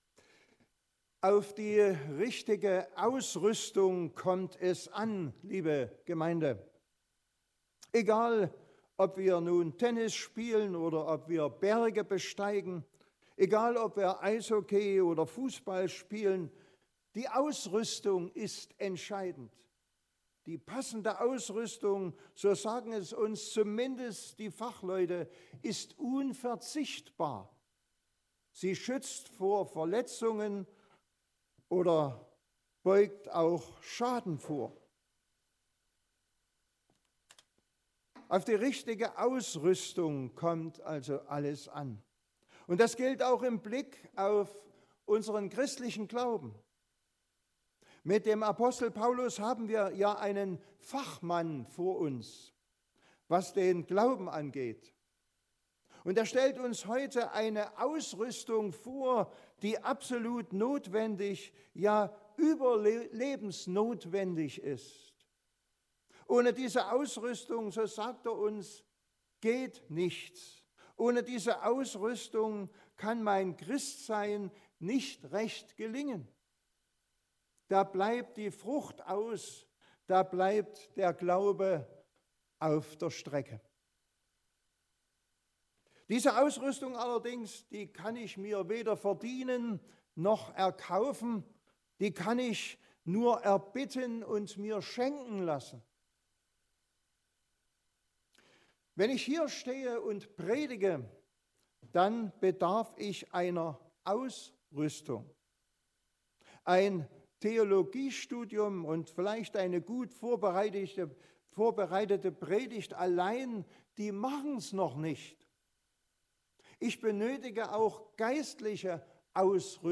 Auch im Glaubensleben ist die richtige Ausrüstung entscheidend. Doch wo bekomme ich diese Ausrüstung her? Diese Predigt soll Antworten liefern.